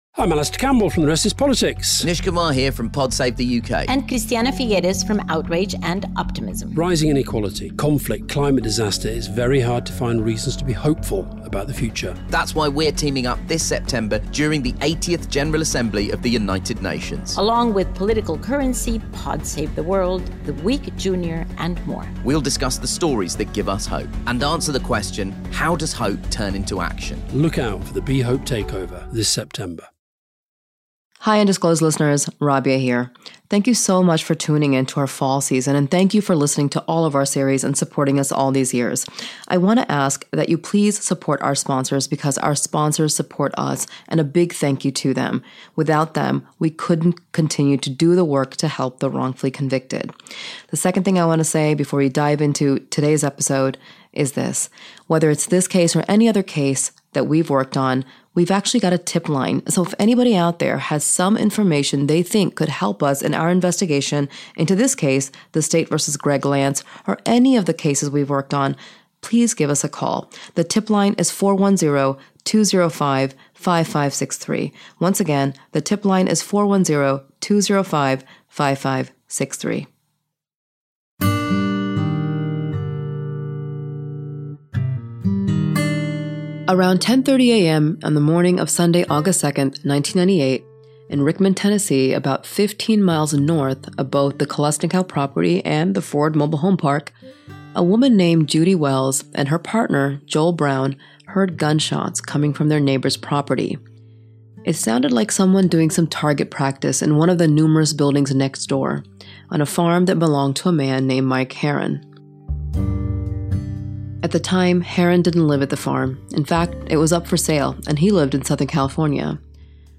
Episode scoring music